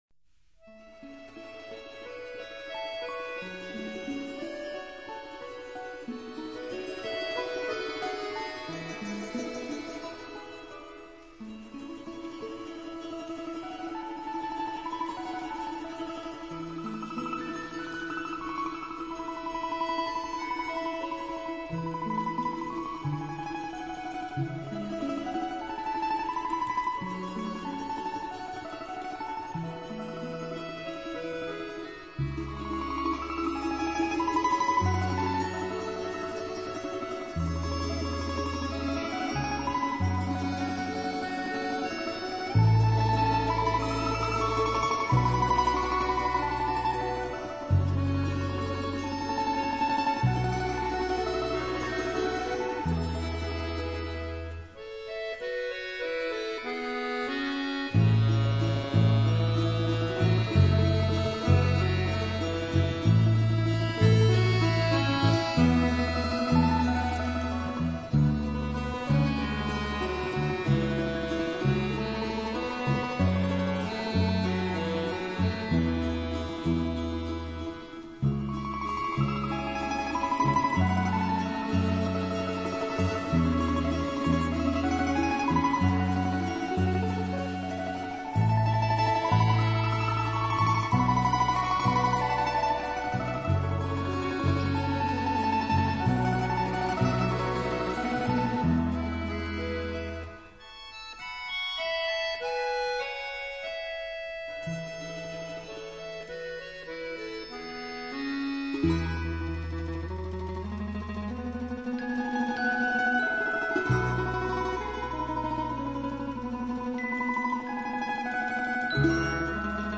三角琴有点像提琴，但只有三条弦线，不用弓来拉奏；它有点像吉他是用手指来弹拨的，琴身呈三角形，并且大小不一。
低音三角琴体积庞大犹如大提琴，但它呈三角形状，在乐队中特别抢眼，小三角琴则轻巧别致，能奏出轻快活泼的音乐。